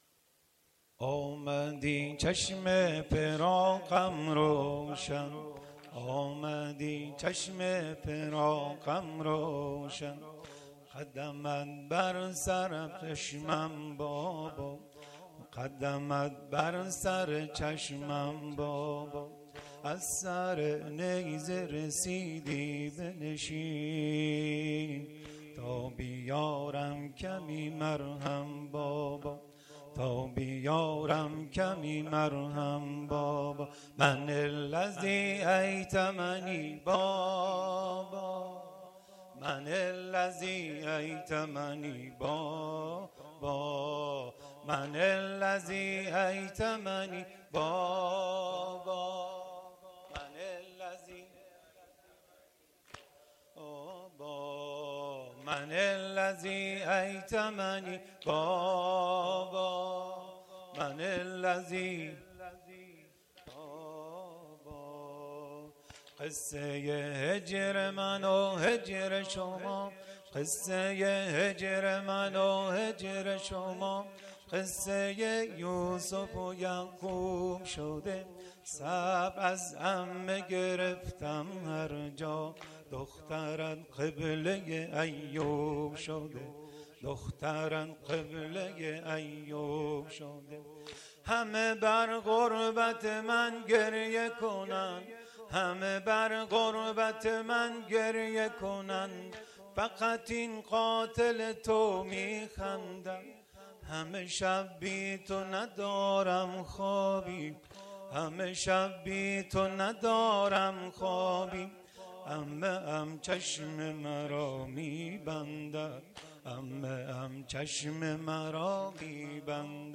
واحد _ من الذی ایتمنی بابا_ شب سوم محرم الحرام۱۴۴۱